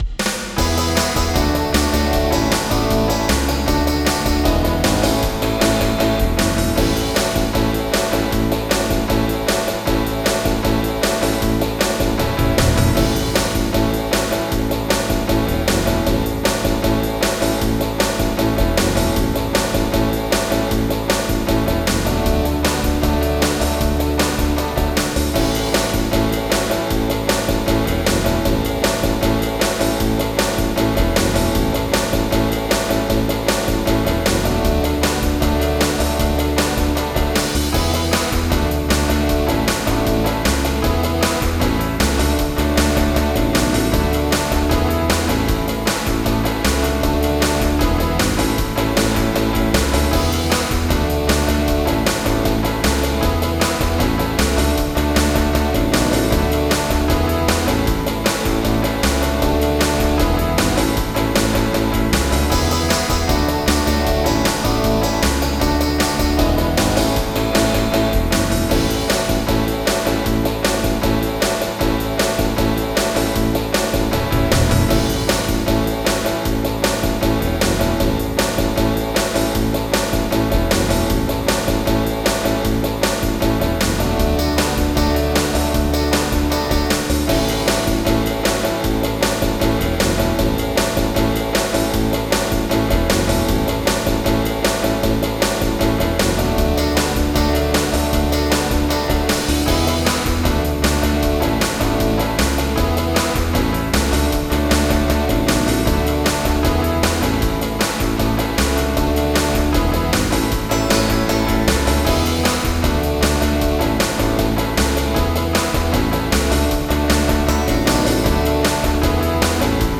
Oldies
MIDI Music File
Type General MIDI